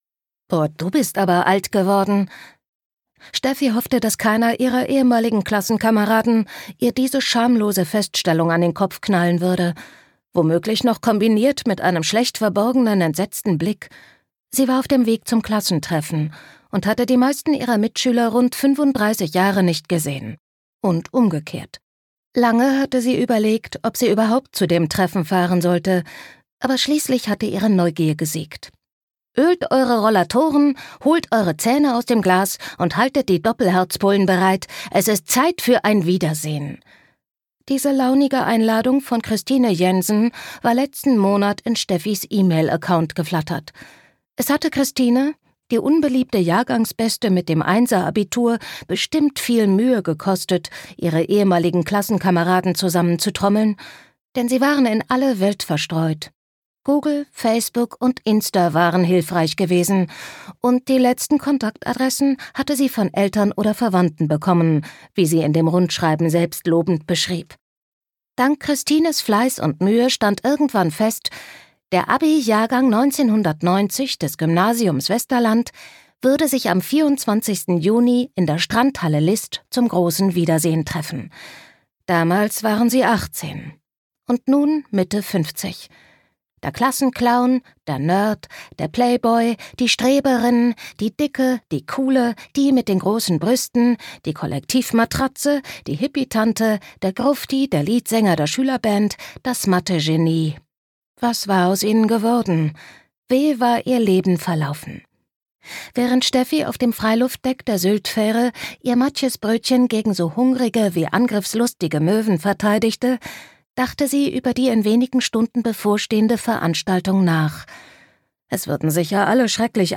Ein Glückshörbuch
Gekürzt Autorisierte, d.h. von Autor:innen und / oder Verlagen freigegebene, bearbeitete Fassung.